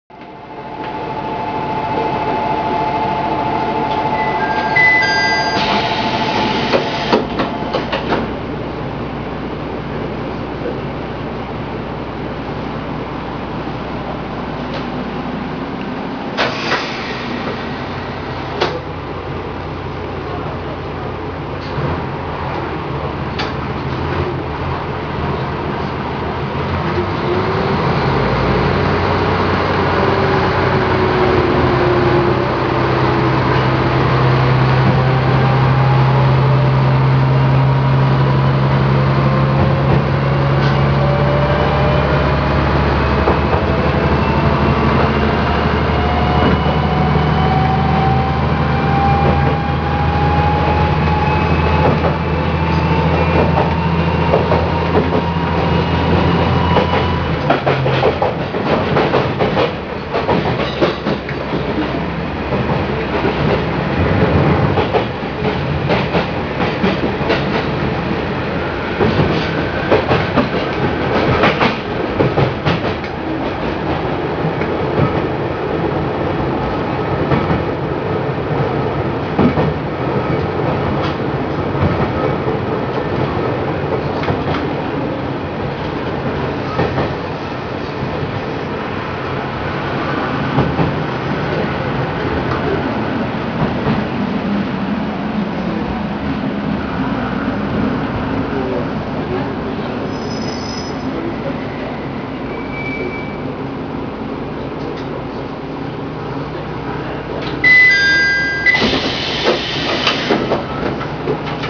〜車両の音〜
・ミキ300形走行音
【湊線】勝田→日工前（1分52秒：607KB）
ごく普通のディーゼルカーの音。